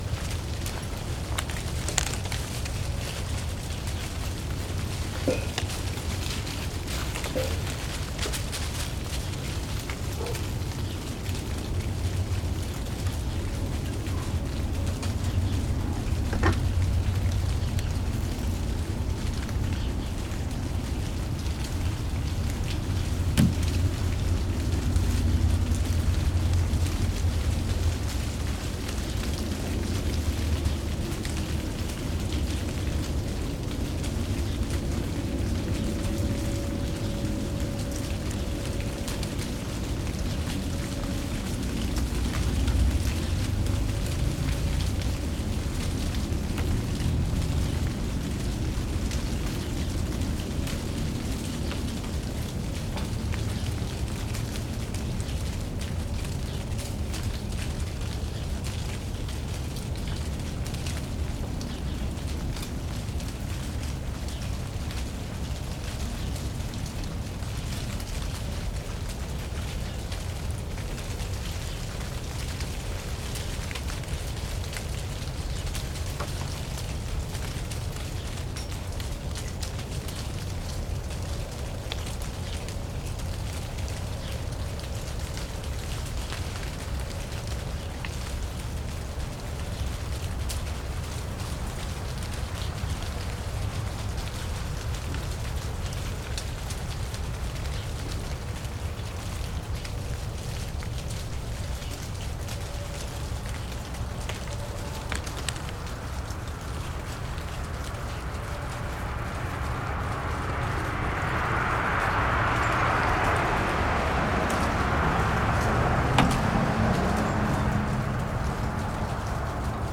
leaves-falling-05
Category 🌿 Nature
fall falling leaves tree sound effect free sound royalty free Nature